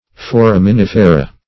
Foraminifera \Fo*ram`i*nif"e*ra\, n. pl. [NL., fr. L. foramen,